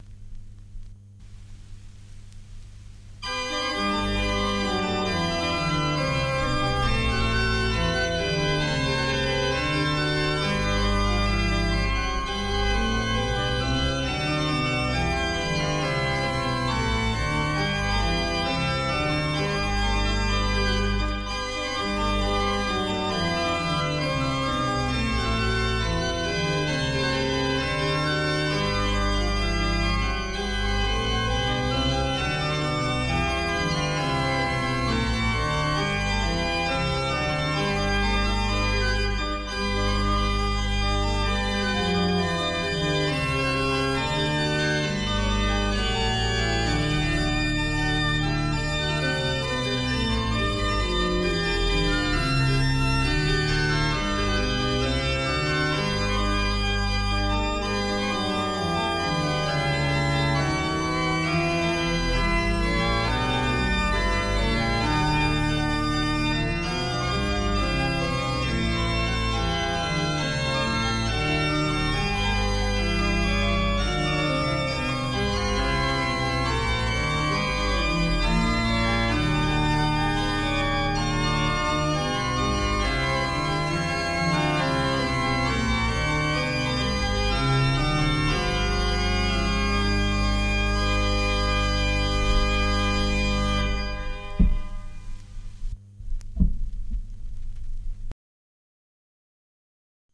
03.-bach-corale-organo.mp3